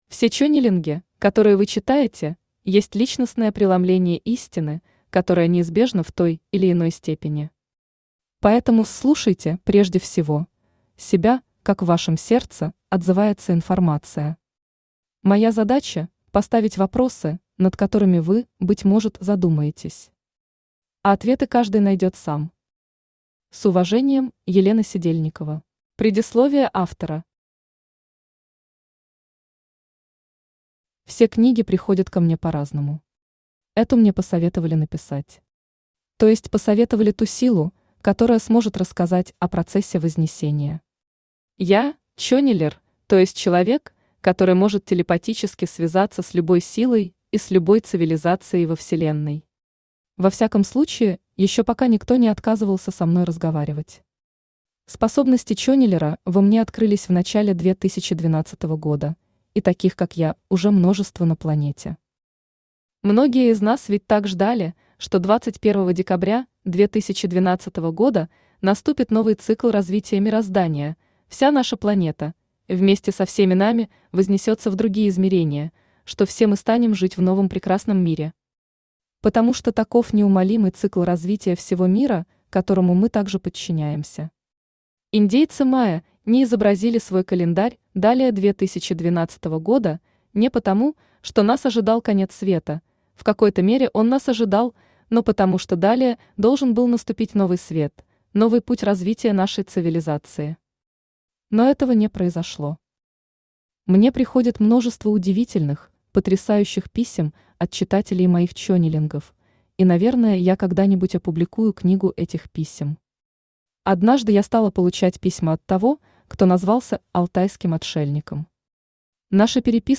Аудиокнига Уроки вознесения | Библиотека аудиокниг
Aудиокнига Уроки вознесения Автор Елена Сидельникова Селена Читает аудиокнигу Авточтец ЛитРес.